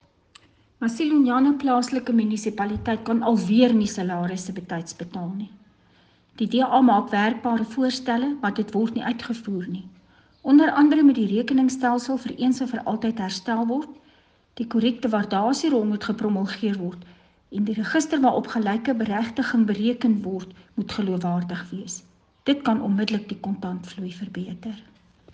Afrikaans soundbites by Cllr Marieta Visser and Sesotho by Karabo Khakhau MP.